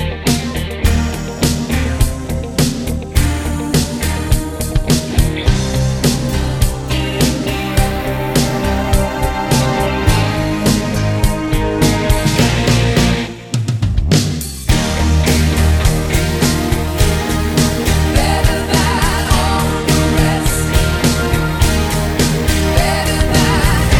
Two Semitones Down Pop (1980s) 4:09 Buy £1.50